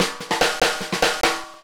Twisting 2Nite Drumz Fill 2.wav